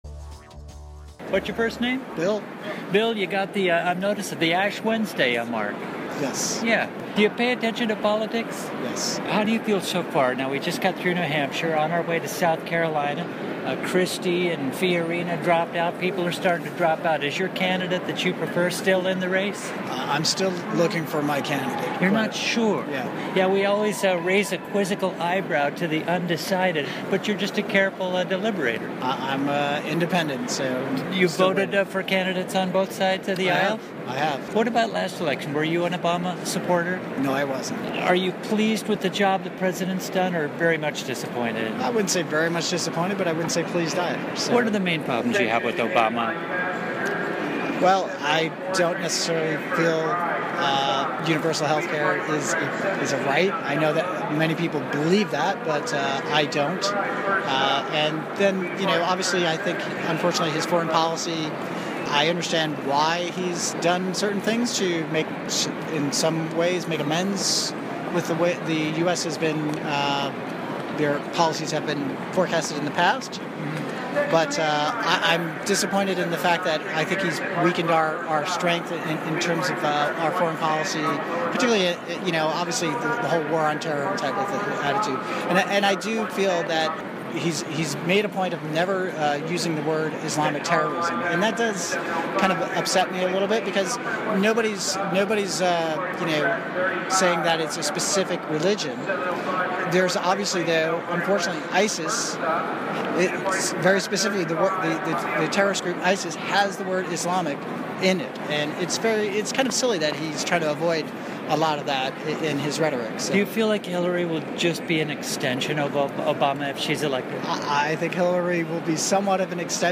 Set: Rubio/Christie exchange courtesy of ABC
Set: Ted Cruz audio courtesy of CNN